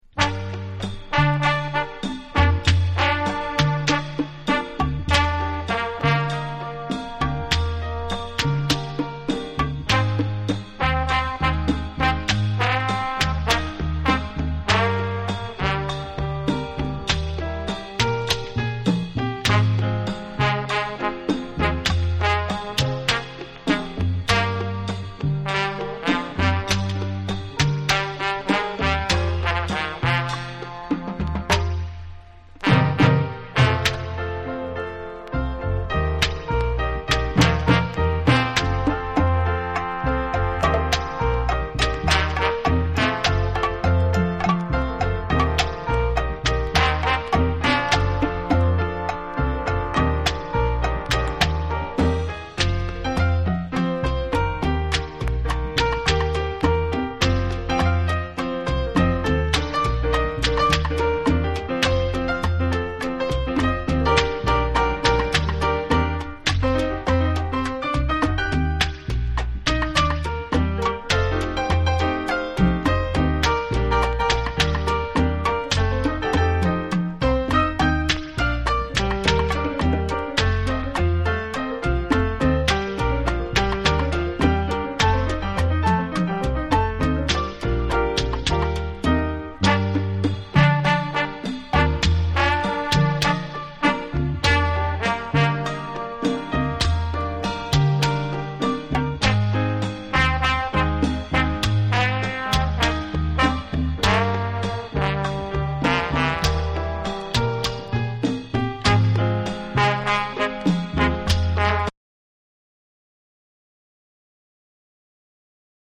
多幸なホーン・フレーズ、「ラララ〜♪」コーラスも飛び出す美メロなトロピカル・ナンバー
WORLD